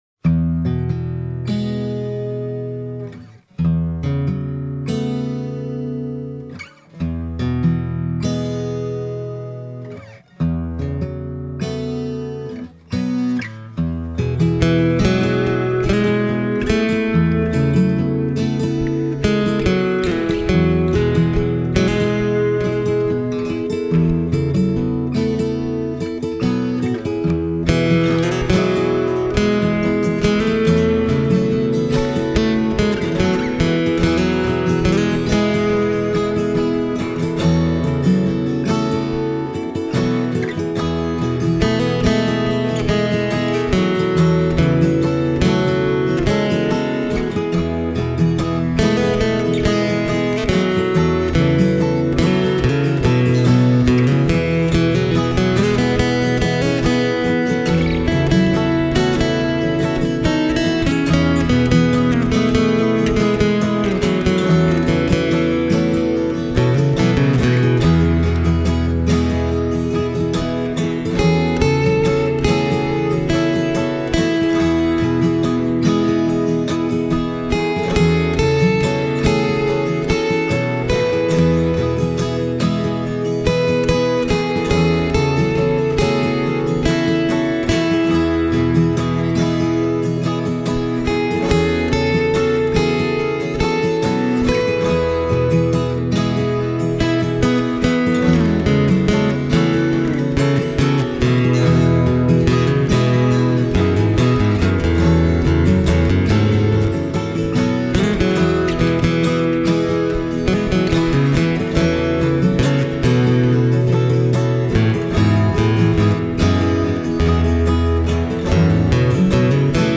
En tiedä johtuuko asia kokonaan lämpökäsitellystä puusta vai johtuuko tämä myös hyvästä designista, mutta Shadow JMS-52 -malli soi heti kättelyssä hyvin ”aikuisella” äänellä.
Shadow JMS-52:lla on ainakin soundi ja soitettavuus kohdillaan, ja myös sen mikrofonijärjestelmä toimii niin kuin pitää:
• all solid cutaway dreadnought
Rhythm guitars recorded with an AKG C3000. Lead guitar recorded direct off the Shadow pickup and preamp.